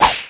punch_thrown.wav